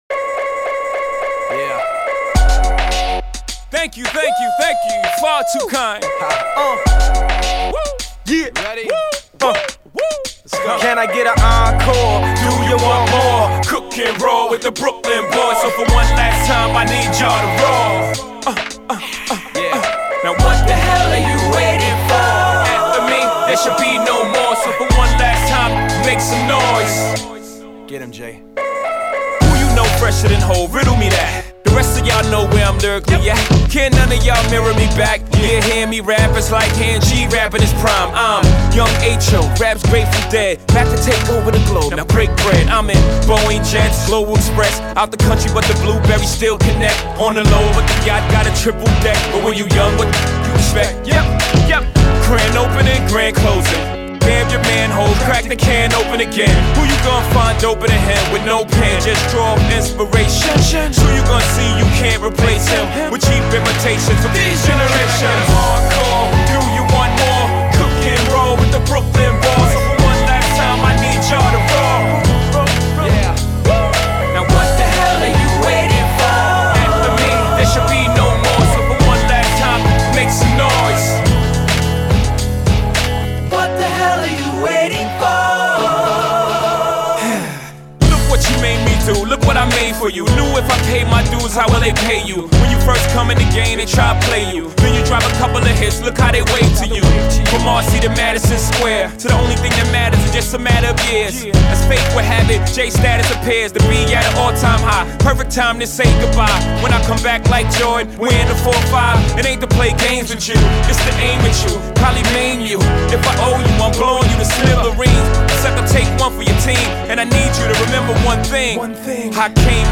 Категория: Альтернатива